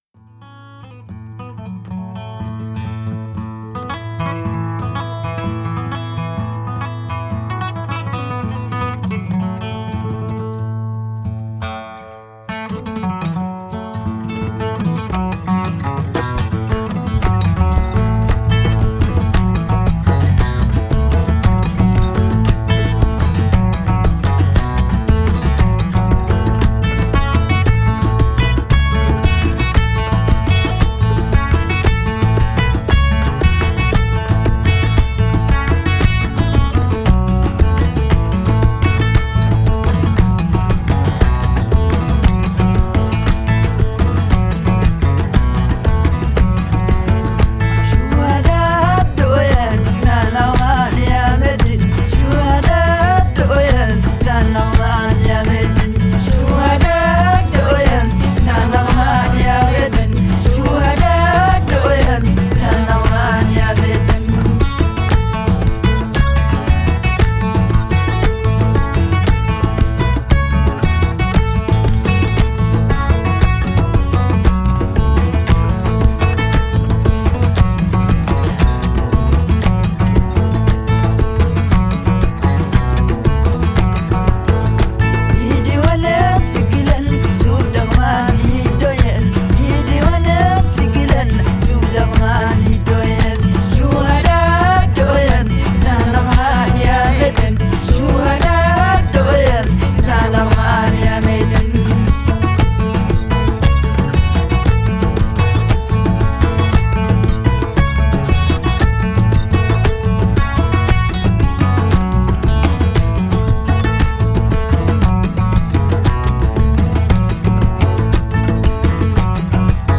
Genre: Desert Blues, Folk, World